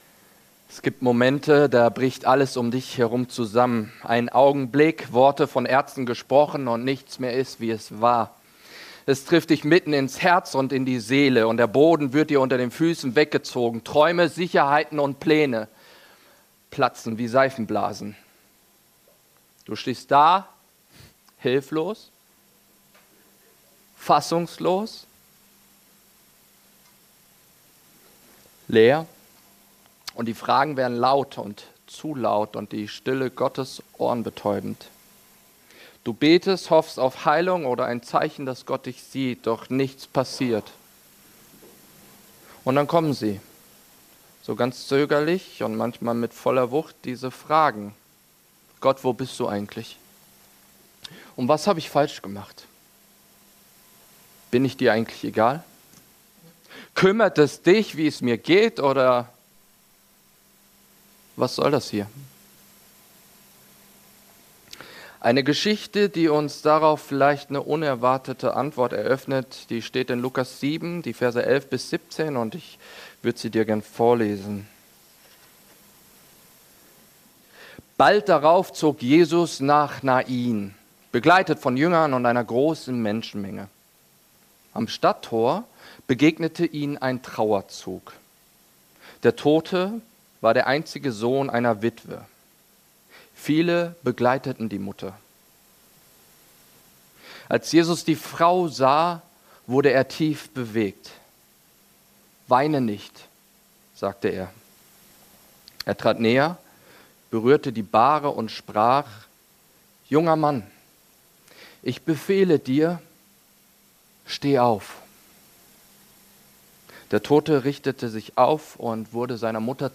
Predigt vom 14. September 2025 in dem 11 Uhr Gottesdienst der freien evangelischen Gemeinde (FeG) Mönchengladbach, unterwegs.